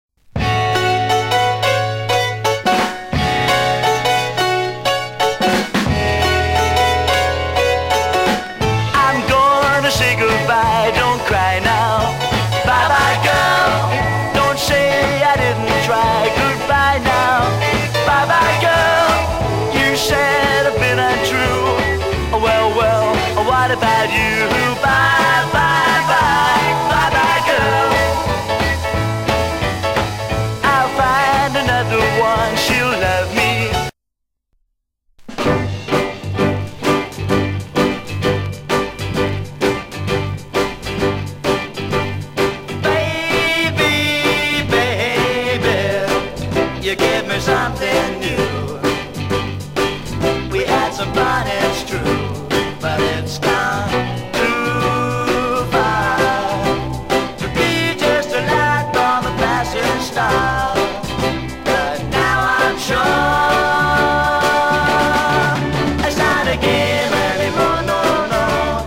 (税込￥4180)   UK BEAT